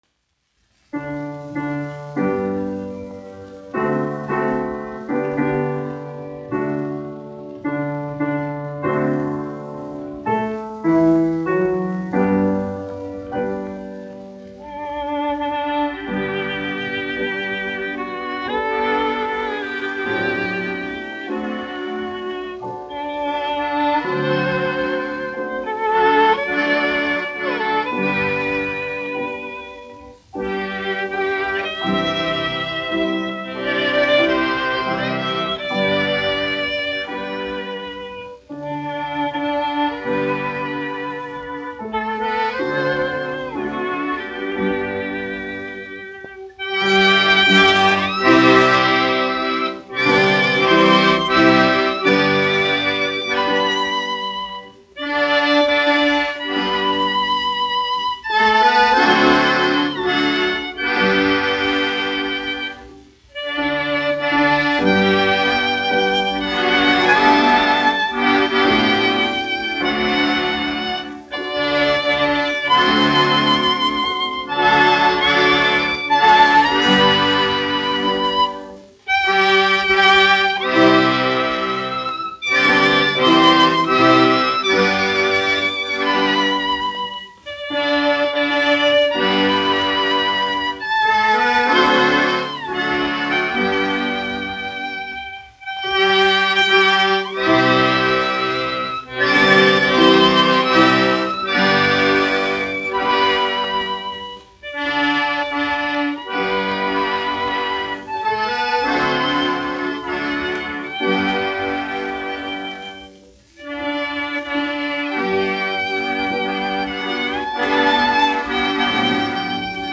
Trio Marcella (mūzikas grupa), izpildītājs
1 skpl. : analogs, 78 apgr/min, mono ; 25 cm
Krievu dziesmas
Skaņuplate